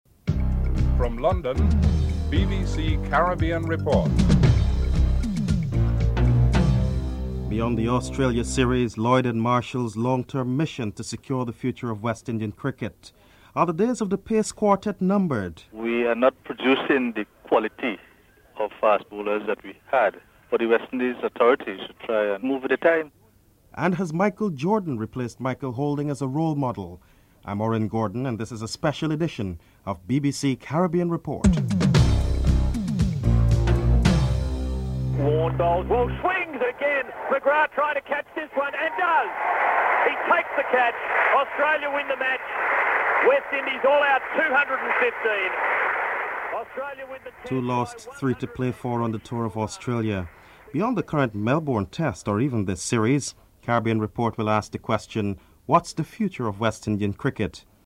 Cricketers Clive Lloyd is interviewed (00:37-03:10)
St. Lucia Premier Vance Amory is interviewed (12:40-15:27)